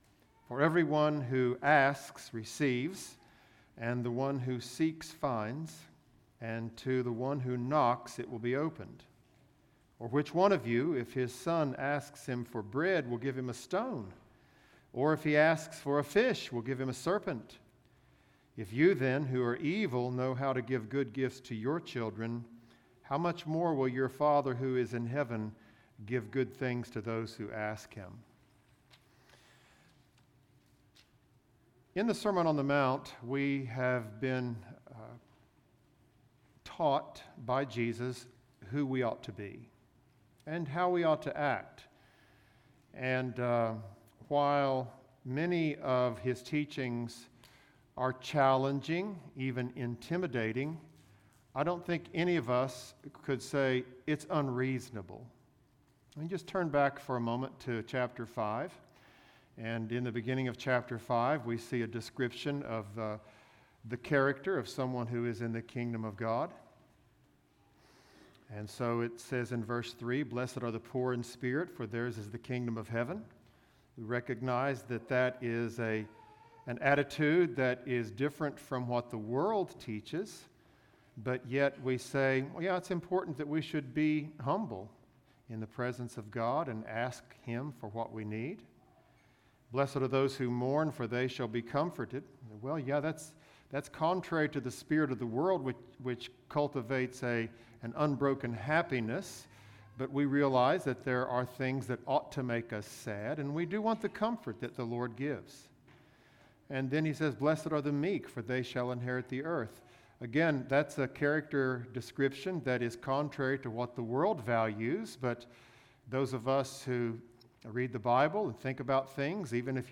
Bullitt Lick Baptist Church - Sermons